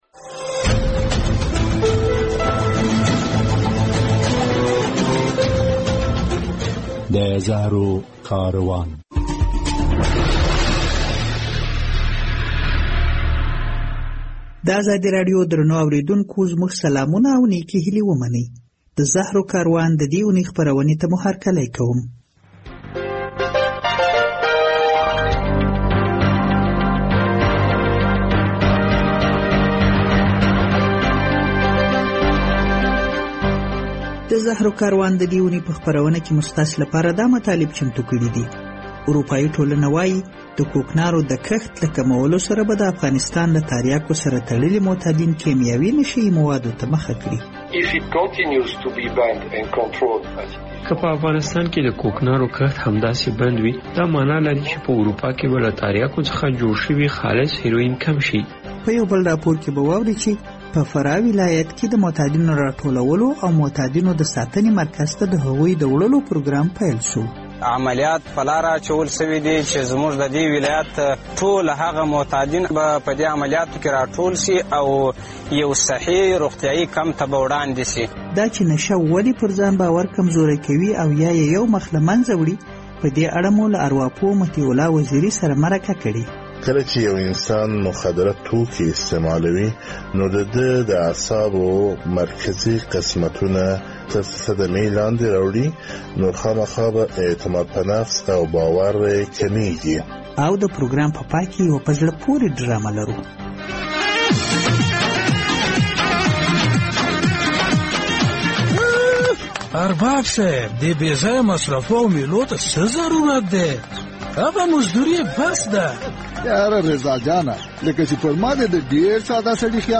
له فراه څخه په یوه راپور کې اورئ چې په دغه ولایت کې د معتادینو د راټولولو لړۍ پیل شوې.